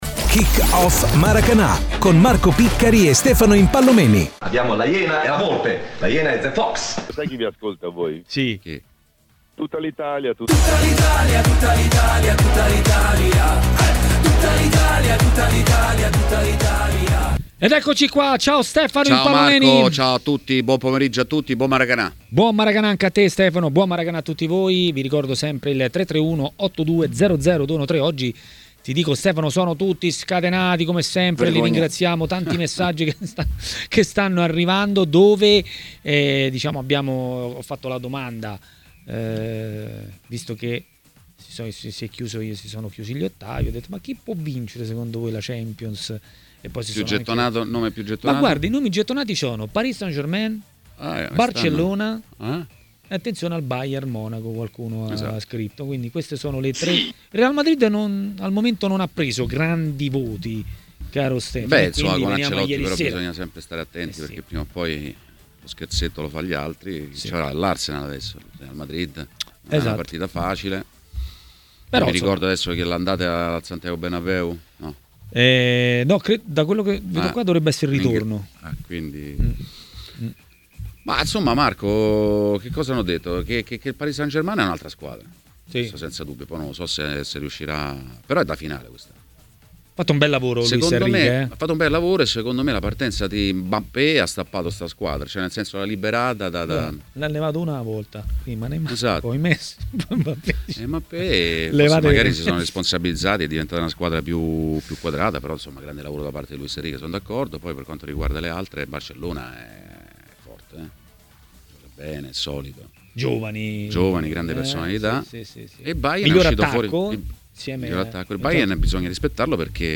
ha parlato a Maracanà, nel pomeriggio di TMW Radio, dei temi del giorno.